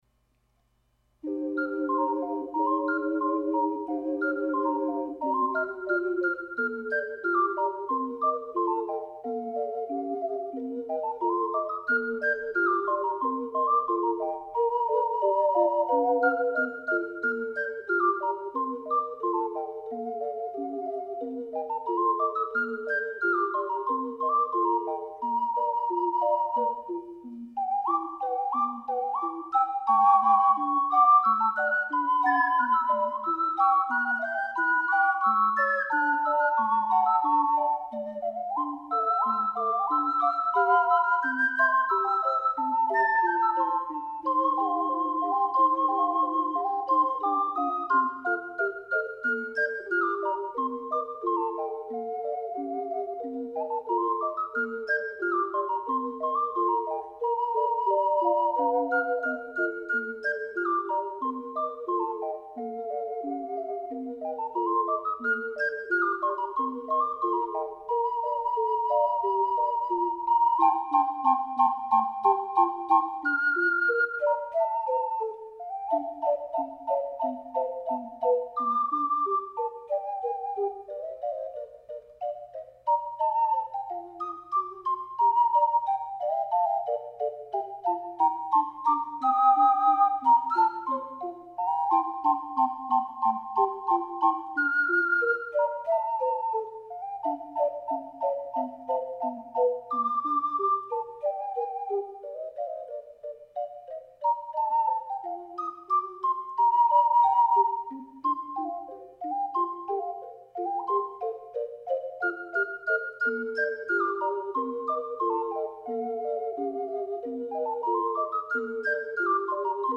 ④BC    楽譜通りの高さです。
吹きやすくて楽しい曲です。